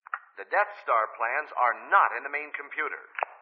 ―Stormtrooper to Darth Vader — (audio)